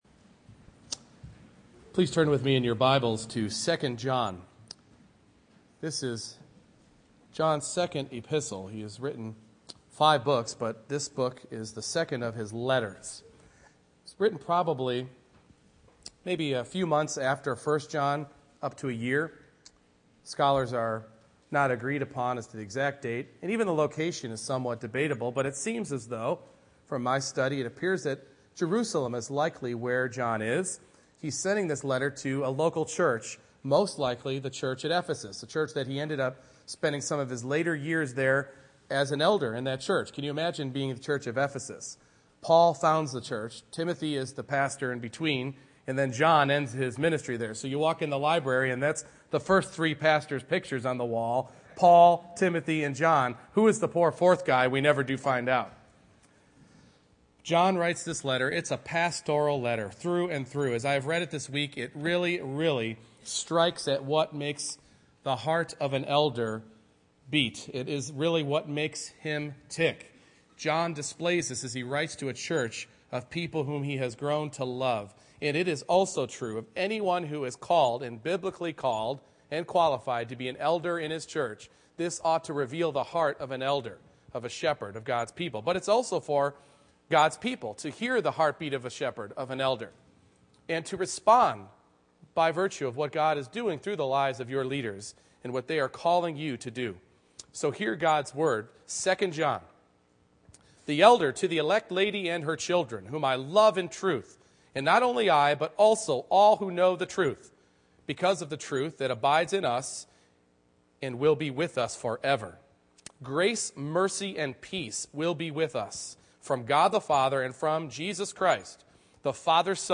2 John 1:1-13 Service Type: Morning Worship John reveals a shepherd's heart concerning his